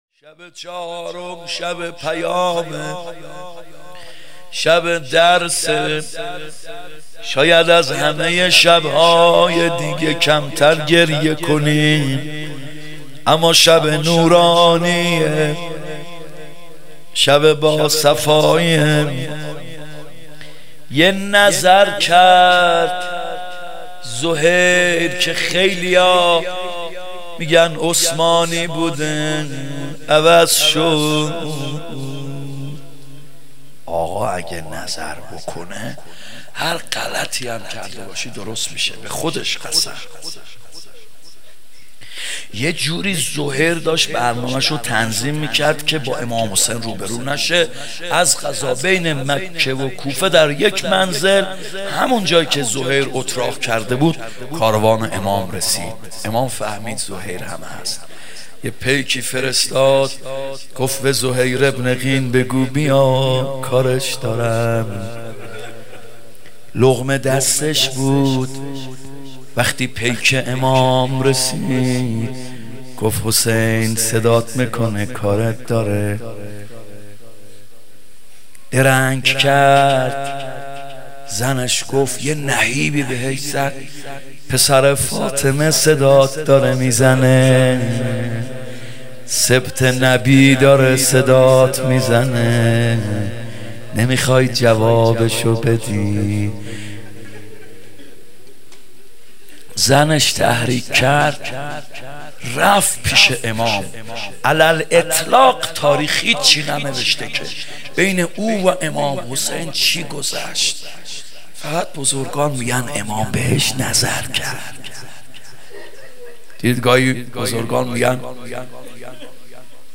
محرم 95(هیات یا مهدی عج)
20 0 محرم 95 شب چهارم روضه